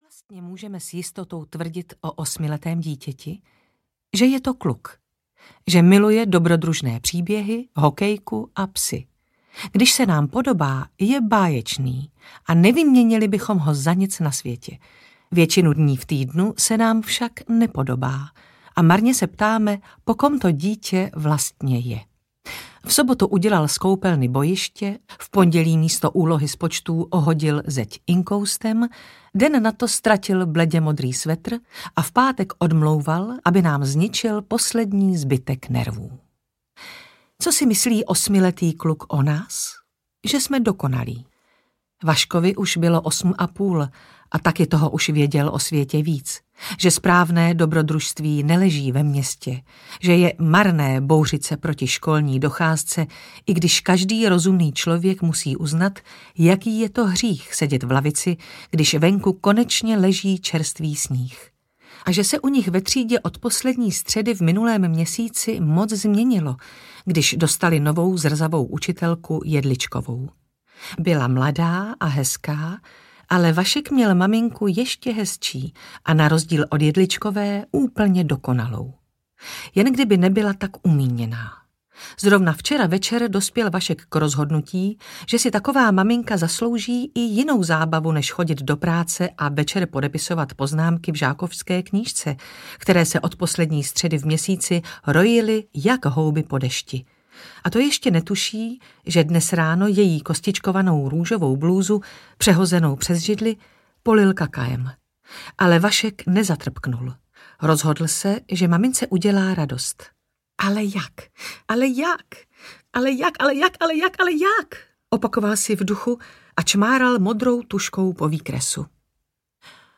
Ukázka z knihy
• InterpretSimona Postlerová